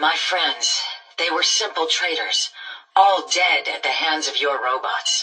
Raw audio from game files. 1